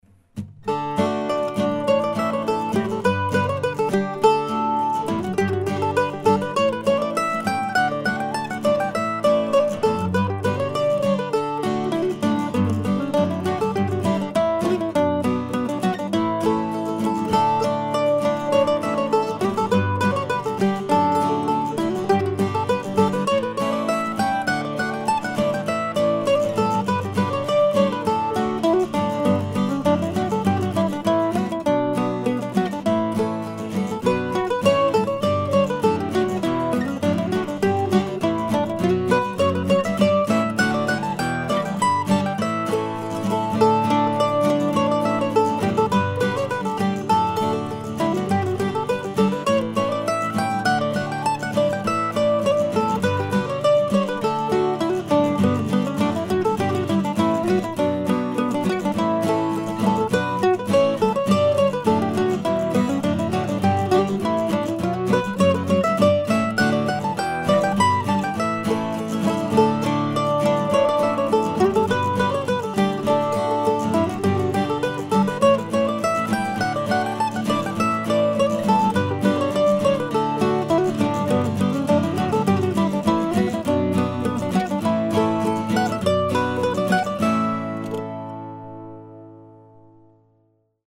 These first tunes have all been recorded on a recently purchased Zoom H4 Handy Digital Recorder. I want to keep this project simple so I've been using the built in condenser mics with no effects or EQ. I'm recording here at home so you might sometimes hear cars going by or household sounds.